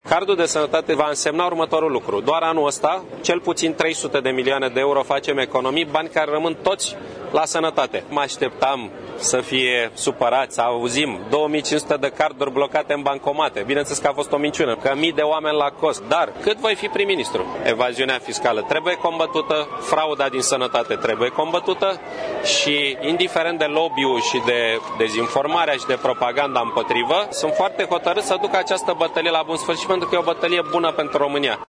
Premierul Victor Ponta spune că introducerea cardului de sănătate va genera economii de 300 milioane euro şi precizează că acţiunea împotriva fraudelor din sistemul medical va continua în pofida lobby-ului si a dezinformărilor.